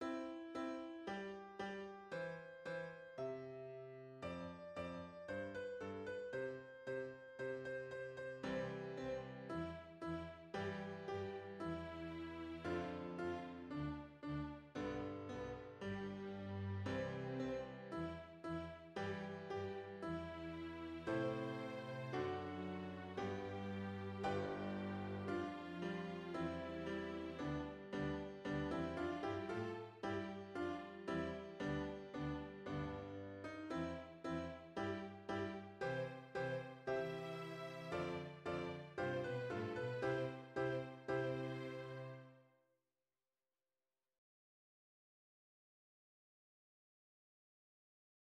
String Practice Pieces